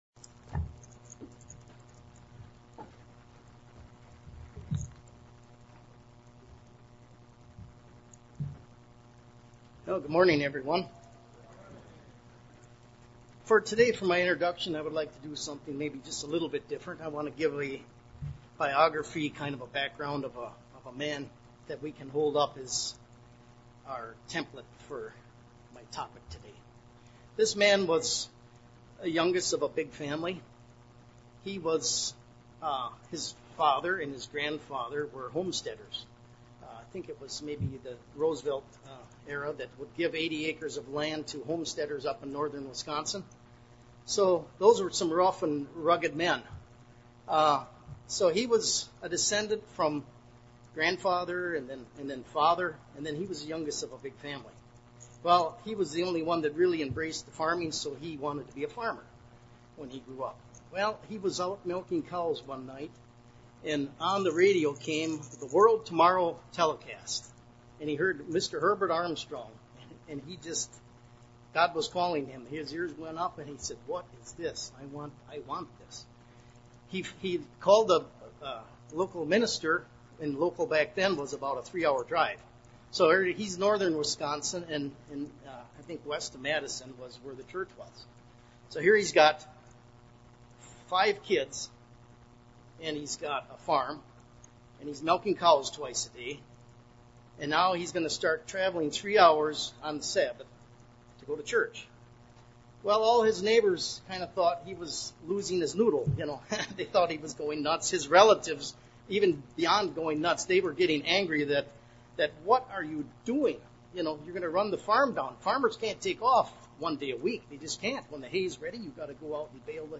This sermon was given at the Pewaukee, Wisconsin 2022 Feast site.